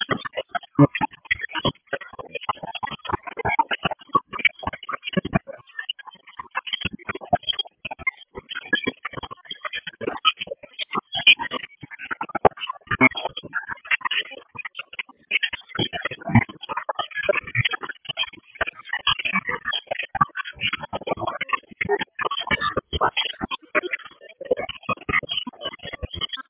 This is demodulated / decoded audio of a P25 Phase 1 encrypted transmission (unknown Algorithm). This audio is NOT DECRYPTED!
P25_Phase_1_Demodulated_Encryption_(Unknown_Algorithm).mp3